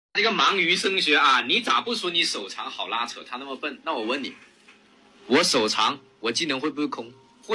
Professionell Röst för Instruktionsvideor
Text-till-tal
Tydlig Berättarröst
Auktoritär Ton
Naturlig kadens och uttrycksfull intonation är specifikt anpassade för instruktionsinnehåll.